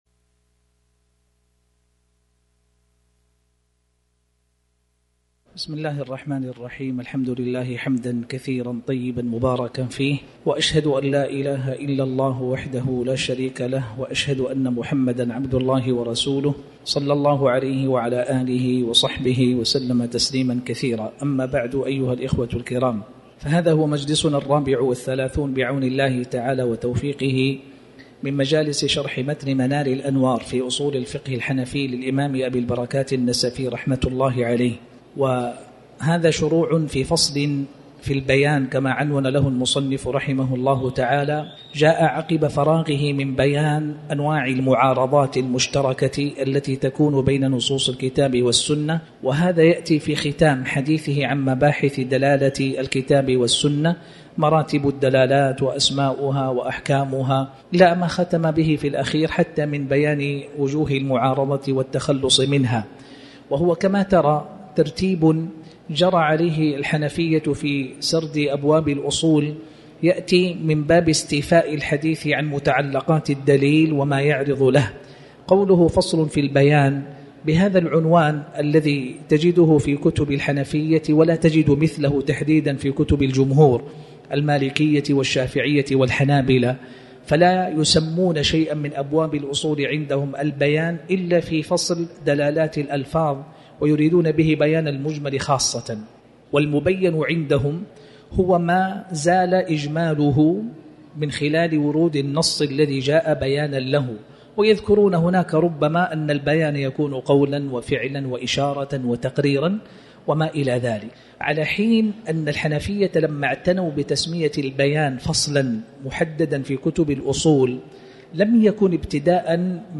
تاريخ النشر ٢١ صفر ١٤٤٠ هـ المكان: المسجد الحرام الشيخ